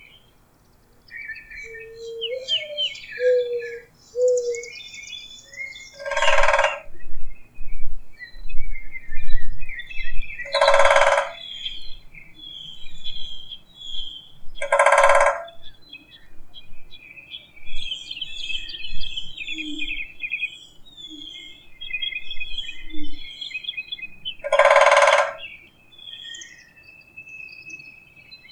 woodpeckersLoop_1.wav